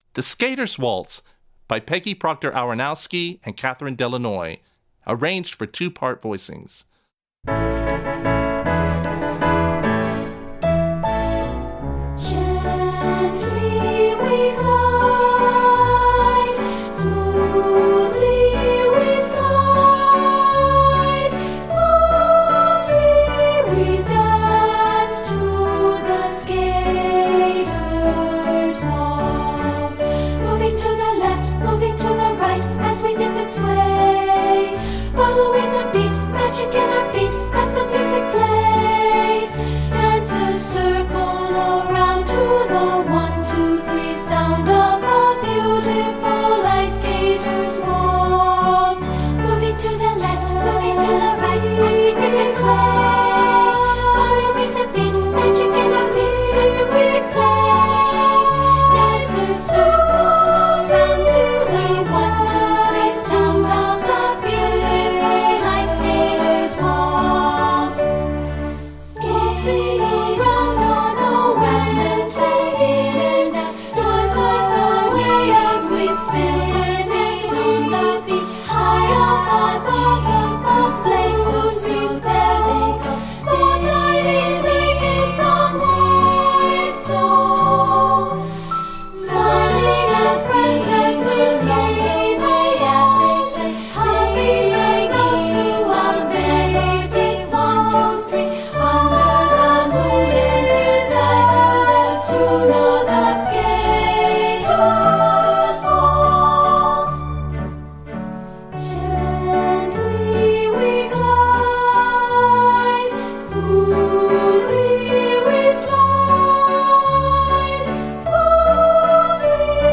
Voicing: Accompaniment CD